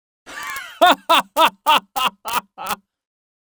Laugh Male
Laugh Male.wav